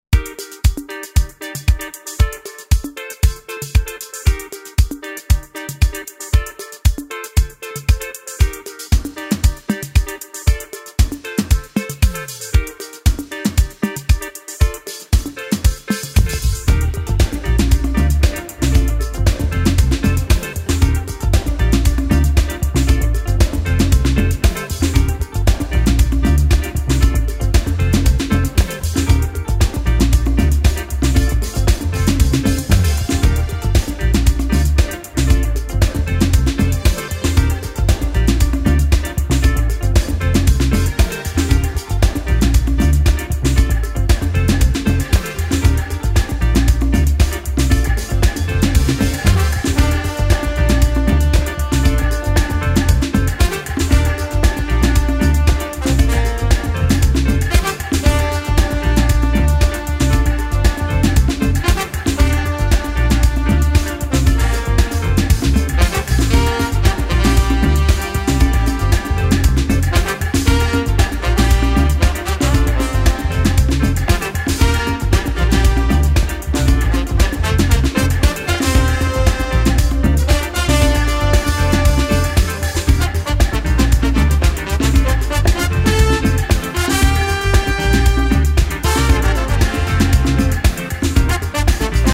Illustrious electronic reworks
house music